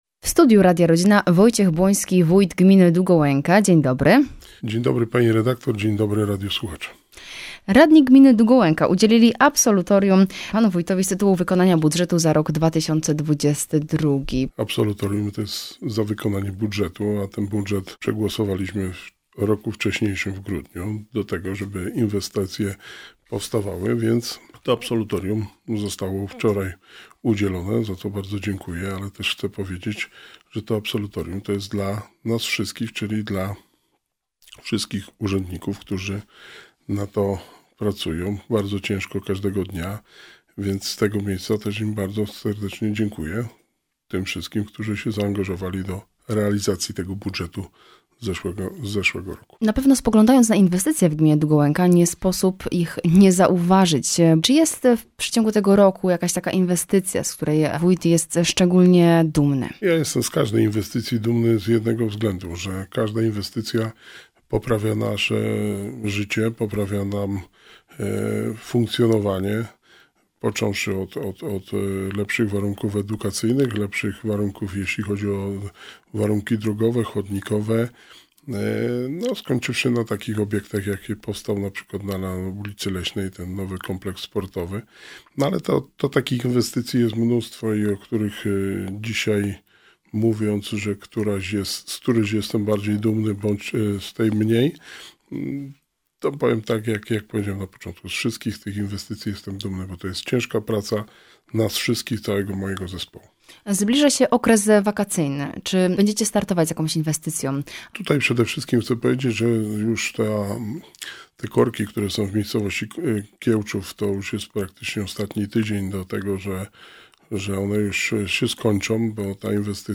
W rozmowie Radia Rodzina z wójtem Gminy Długołęka – Wojciechem Błońskim rozmawiamy o bieżących wydarzeniach, planach inwestycyjnych oraz akcji „Oddaj Krew na wakacje”. Podsumowaliśmy także loterię „Rozlicz PIT w gminie Długołęka”.
01-wojt-gminy-rozmowa.mp3